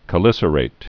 (kə-lĭsər-āt, -ĭt)